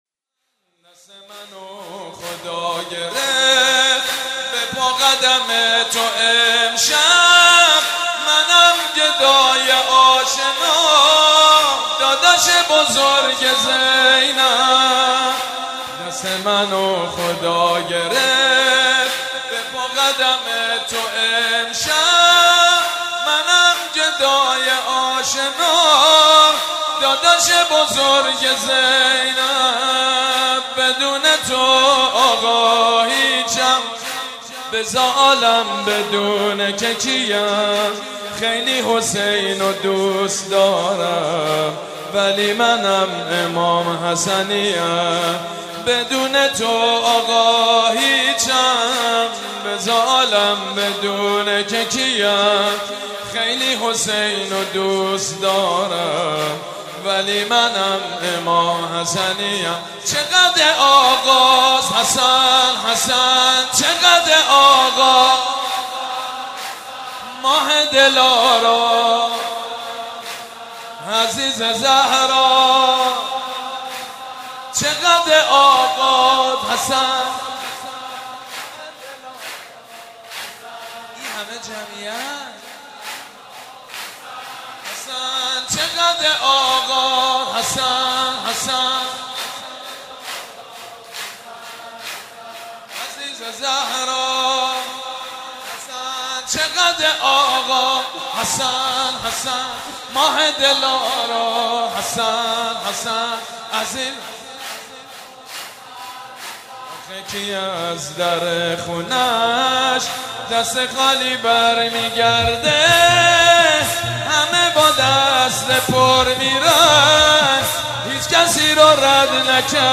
همزمان با فرارسیدن ولادت حضرت امام حسن مجتبی(ع) فایل صوتی گلچین مولودی با نوای مداحان اهل بیت (ع) را می‌شنوید.